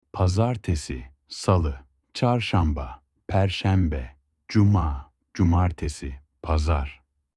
روزهای هفته به ترکی استانبولی و تلفظ آن‌ها